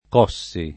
cuocere [kU0©ere] v.; cuocio [kU0©o], -ci — pop. cocere [kere]: cocio [ko], coci — pass. rem. cossi [